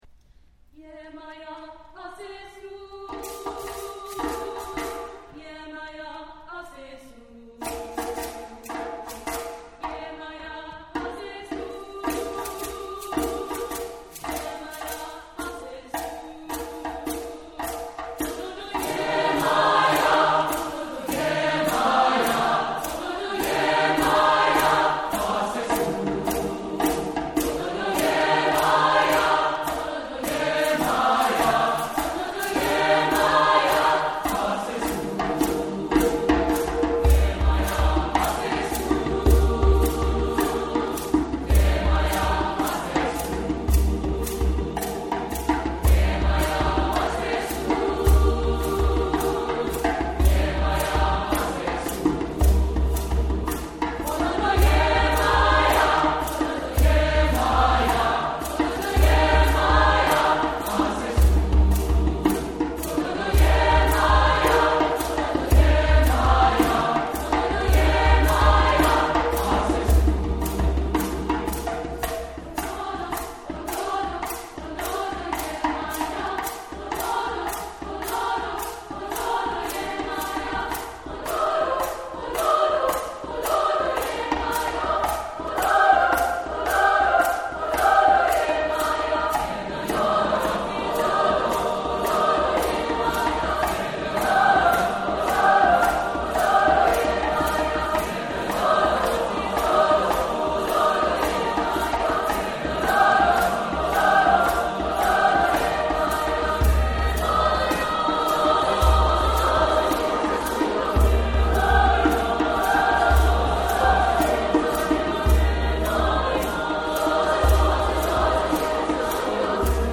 Composer: Yoruba Chant
Voicing: SATB and Piano